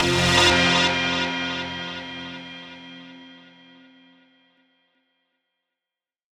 SOUTHSIDE_percussion_midnight_hour_F.wav